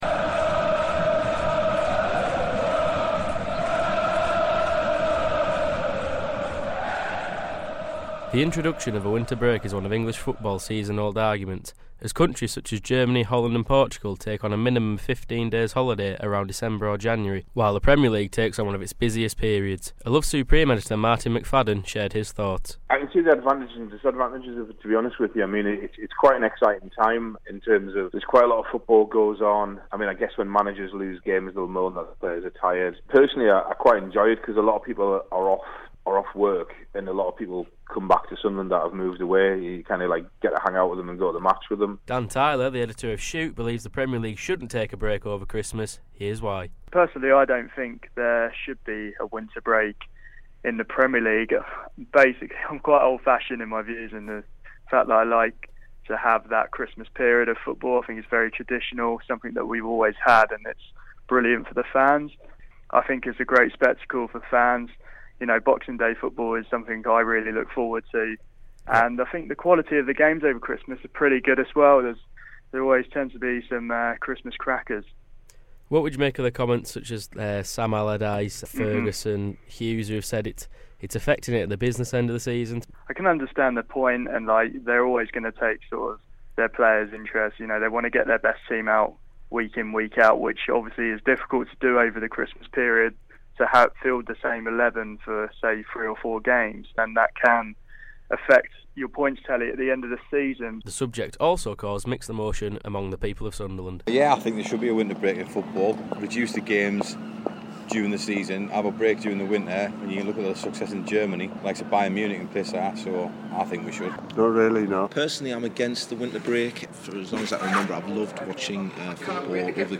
the people of Sunderland